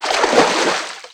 MISC Water, Splash 06.wav